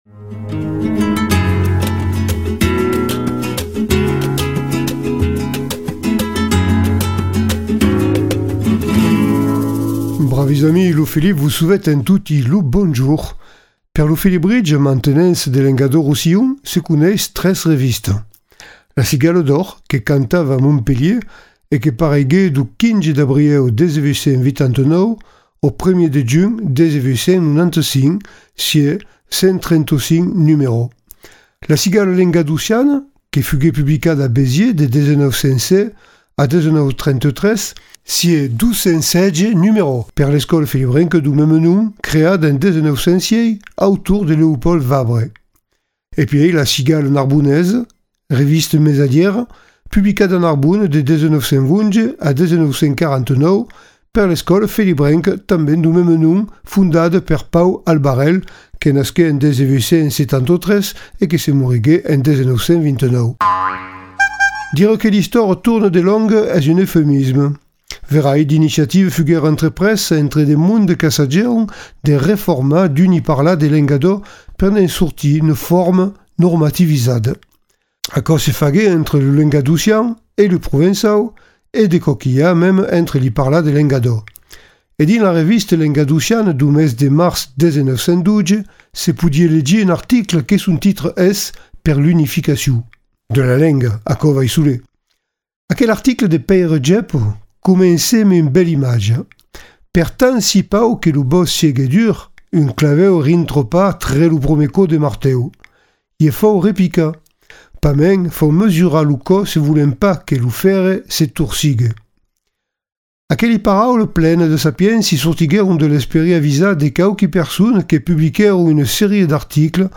Votre chronique en Occitan